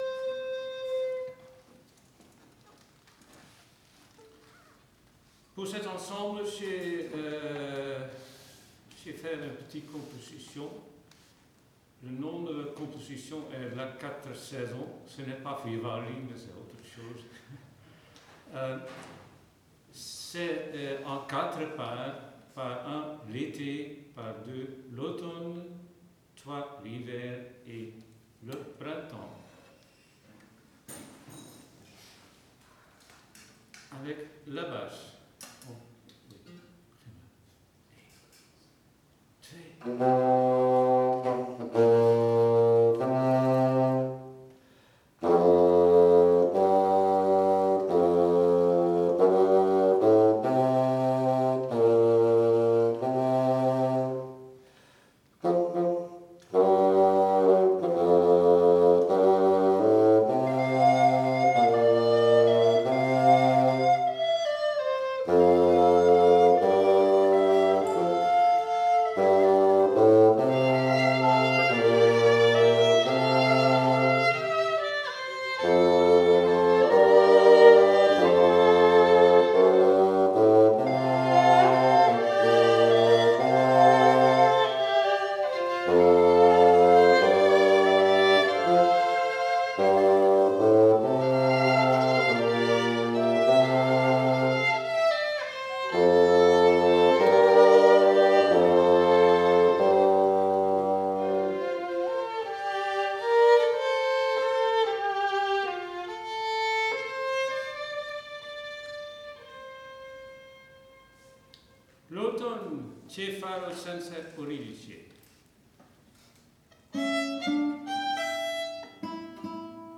Programme des auditions
Petit ensemble